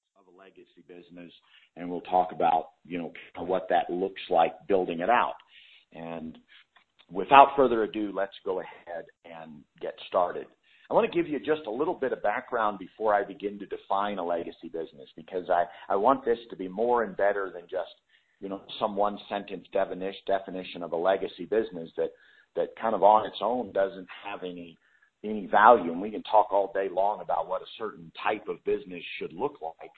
This is an audio course containing 55+ mins of inspiring lessons thats going to teach you How To Build a Legacy Business.